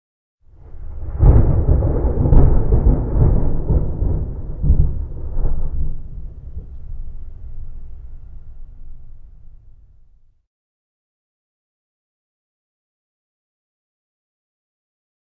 雷声.ogg